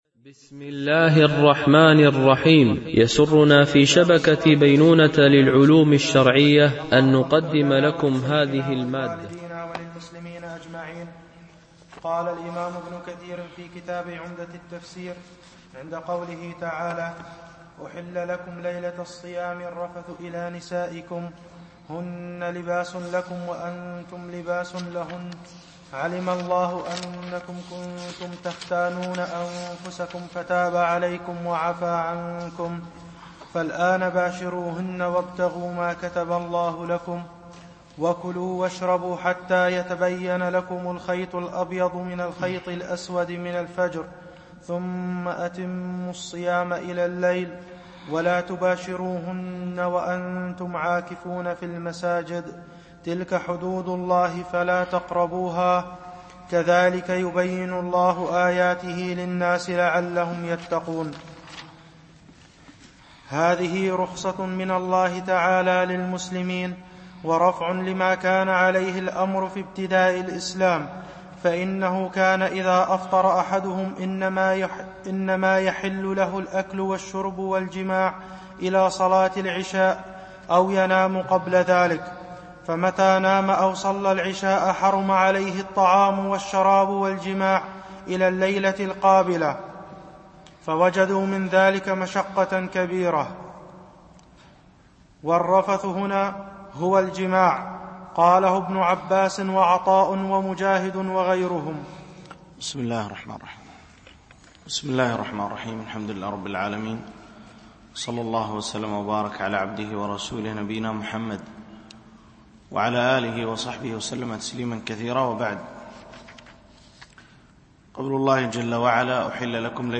شرح مختصر تفسير ابن كثير (عمدة التفسير) - الدرس 28 (سورة البقرة الآية 187 - 188)